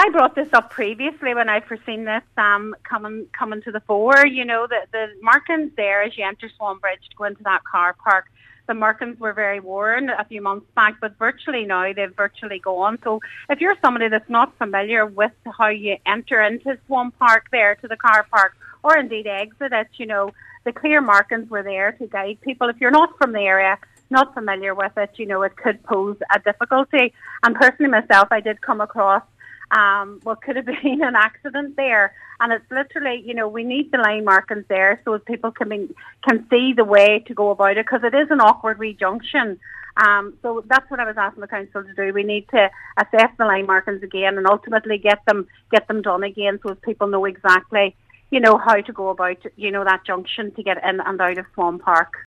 An Inishowen Municipal District meeting has been told there’s a need for road markings to be improved at the entrance to the Swan Park car park.
Councillor Joy Beard says the markings have faded in recent months, and the situation now is people who are not familiar with the junction can be easily confused.